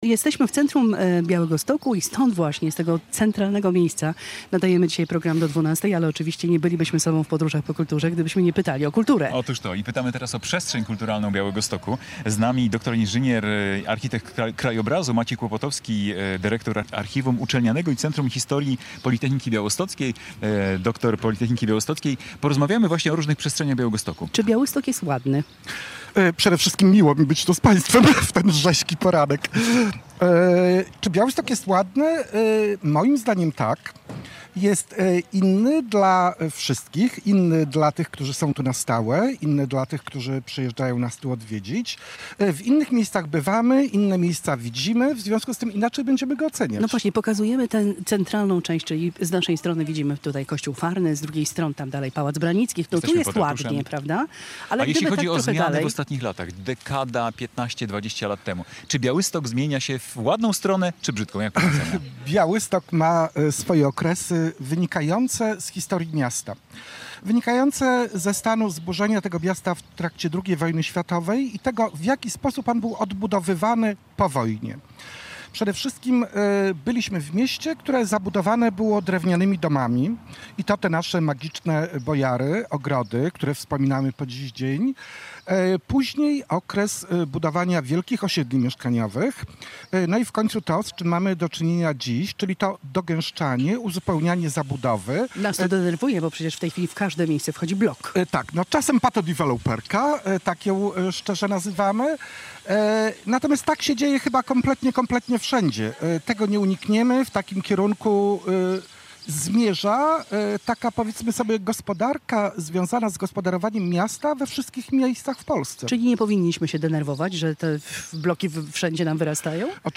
Polskie Radio Białystok w niedzielę otworzyło mobilne studio przy Ratuszu. Od 9:00 do 12:00 w audycji Podróże po kulturze sprawdzaliśmy, jak zmienia się kulturalna mapa Białegostoku.